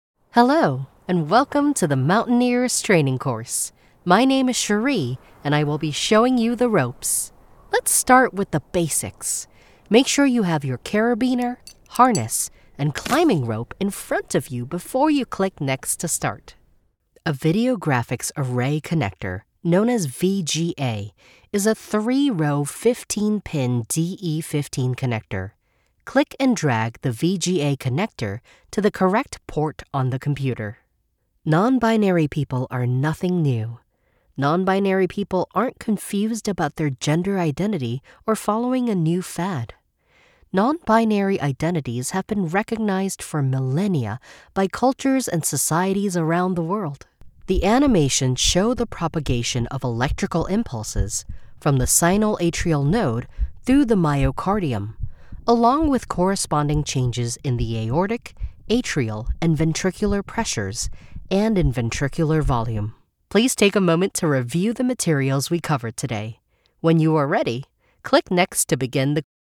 Teenager, Young Adult, Adult
Has Own Studio
singapore | natural
standard us | natural
e-learning